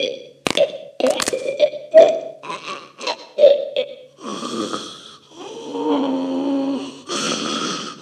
player_zombie_normal_female_idle.mp3